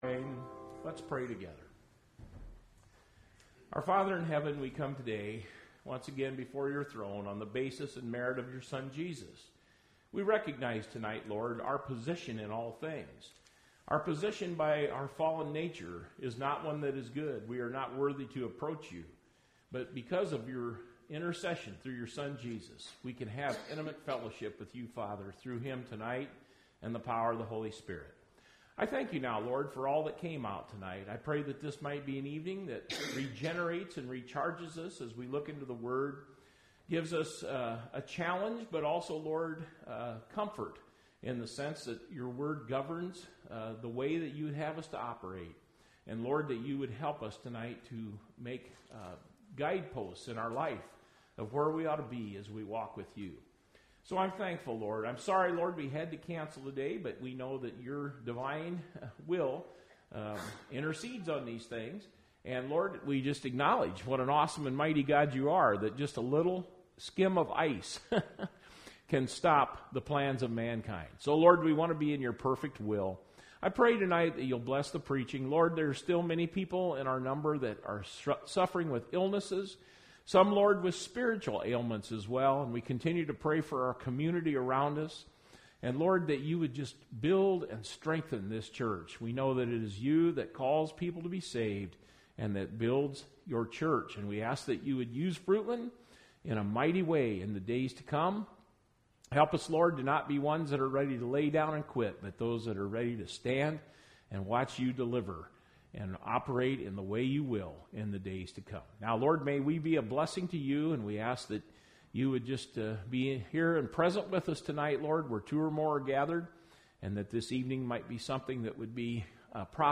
Service Type: Sunday Evening Message and Songs Topics: Christian Living , Separation